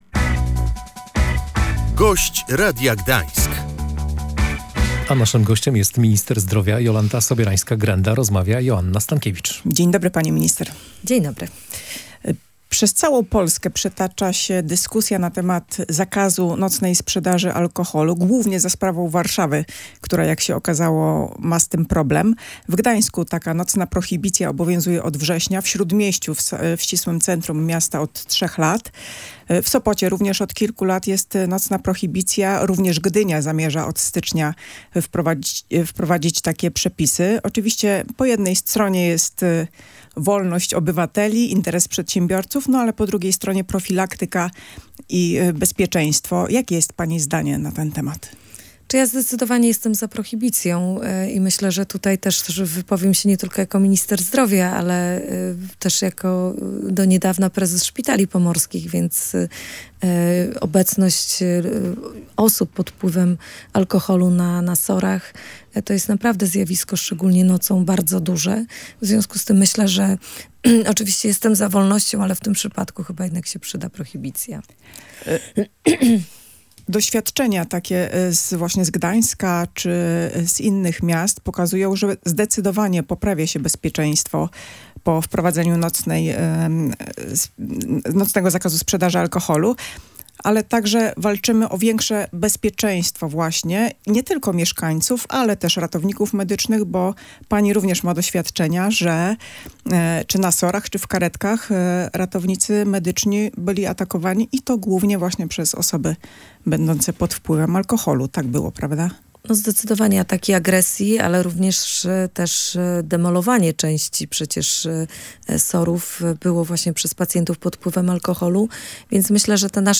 Wprowadzenie częściowej prohibicji zwiększy bezpieczeństwo Polaków – mówiła w Radiu Gdańsk minister zdrowia.